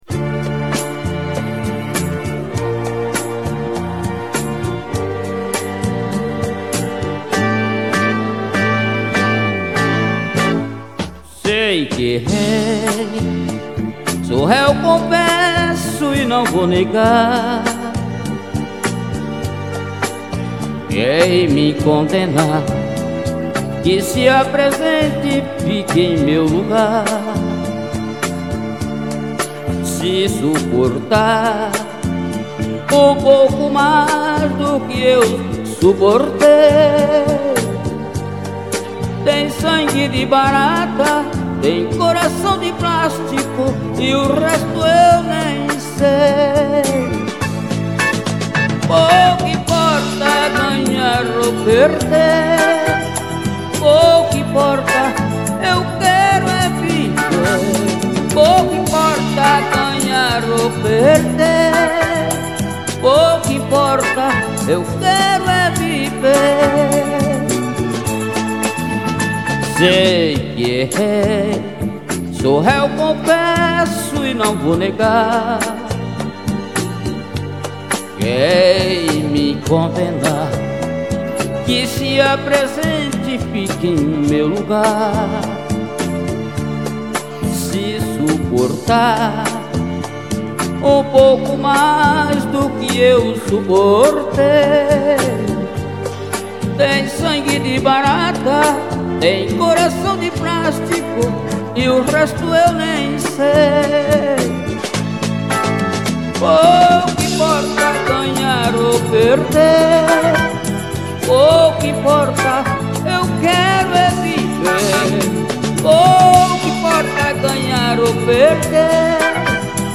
EstiloArrocha